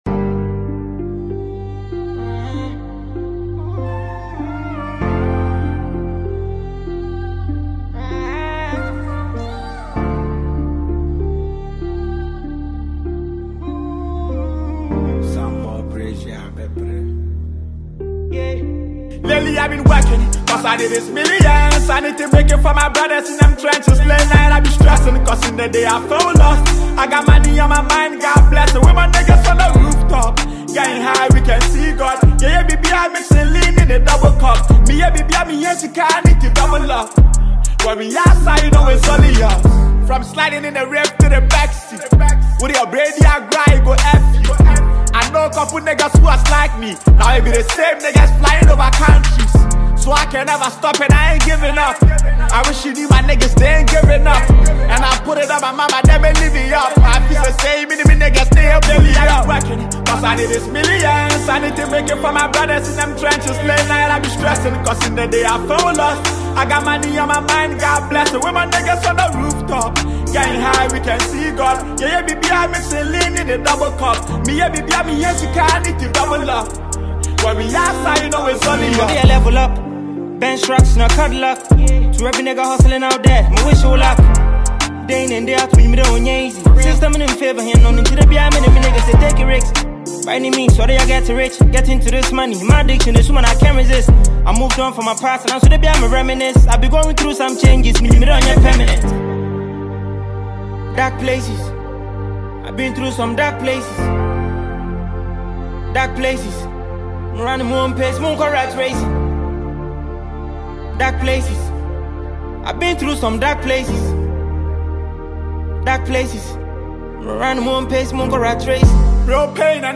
Ghana Music
for a masterclass in modern Ghanaian Hip-Hop and Trap.